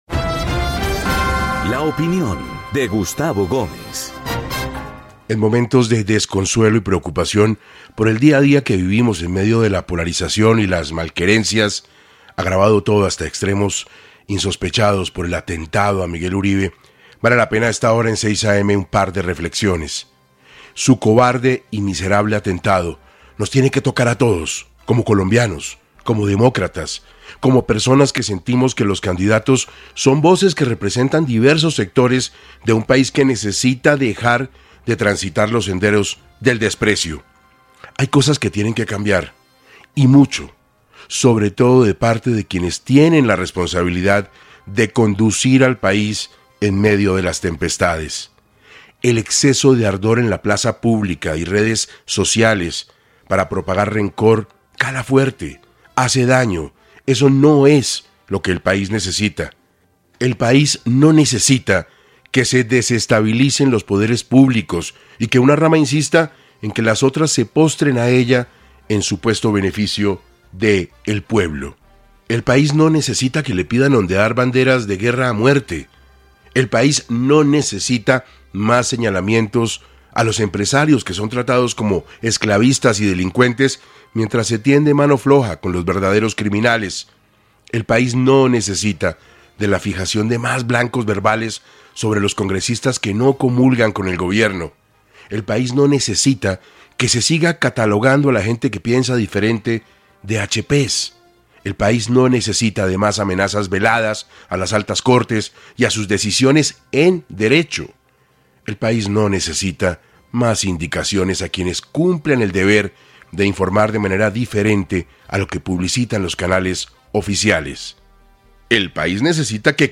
Urge bajar los niveles de rencor y malquerencias, y fortalecer la defensa de las instituciones y la Constitución. Editorial de Gustavo Gómez en 6AM.